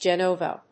/ˈdʒɛnovʌ(米国英語), ˈdʒenəʊvʌ(英国英語)/